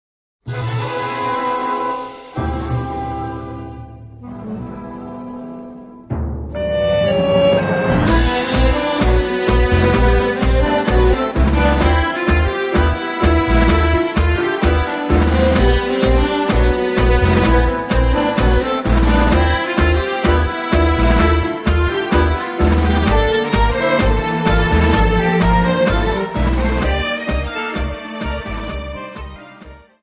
Dance: Tango Song